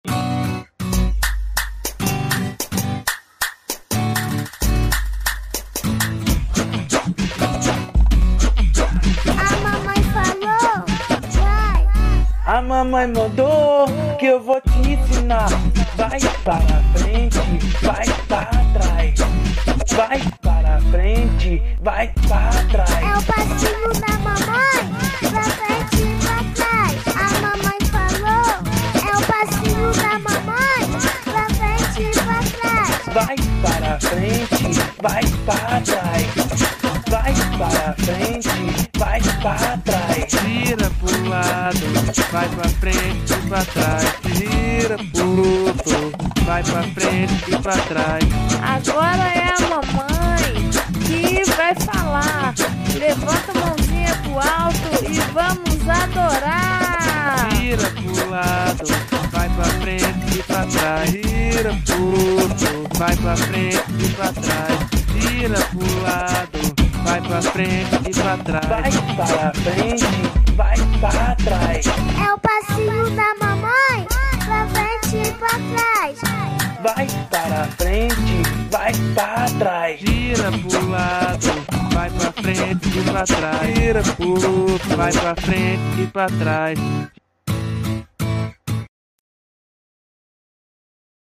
EstiloFunk
Composição: Funk Gospel.